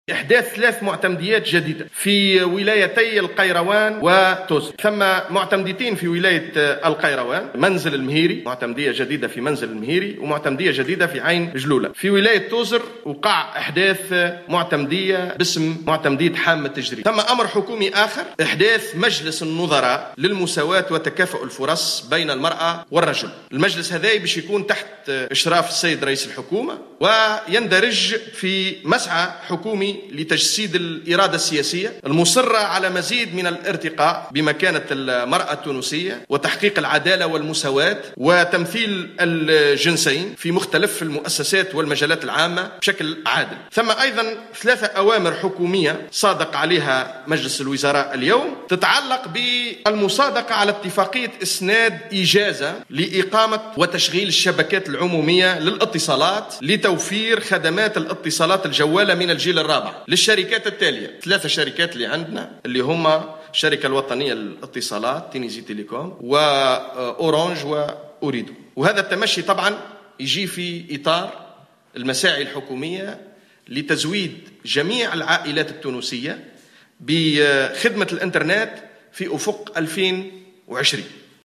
أعلن الناطق الرسمي باسم الحكومة خالد شوكات في تصريح اعلامي اليوم الاربعاء بقصر الحكومة عقب اجتماع مجلس الوزراء عن احداث 3 معتمديات جديدة بولايتي القيروان وتوزر اثنتان منها بالقيروان وهي عين جلولة ومنزل المهيري والثالثة في توزر وهي منطقة حامة الجريد.